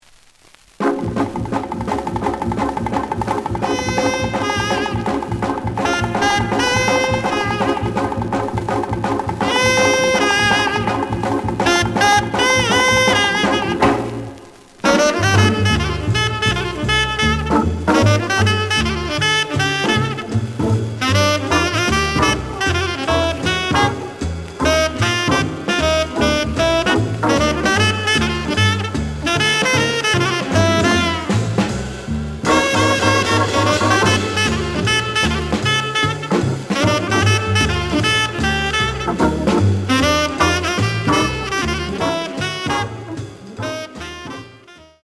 The audio sample is recorded from the actual item.
●Genre: Modern Jazz
Slight noise on both sides.